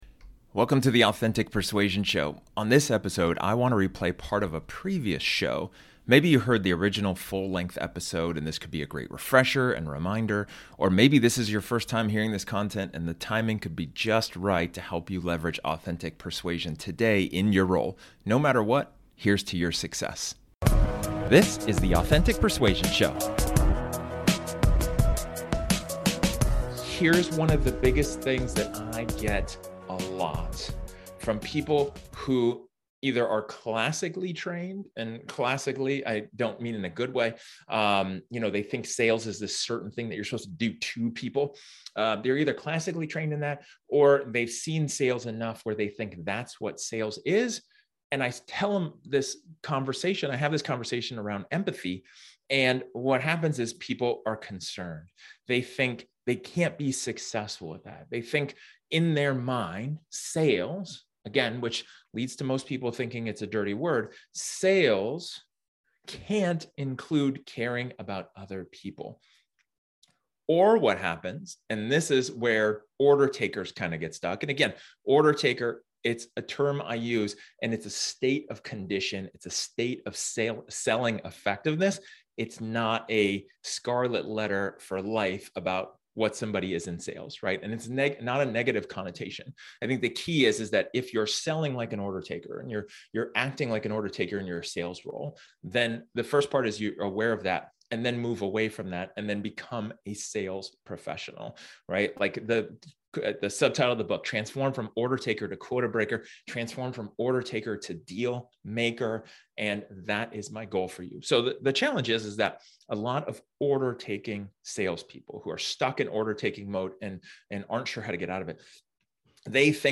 This episode is an excerpt from one of my training sessions where I talk about empathizing for success.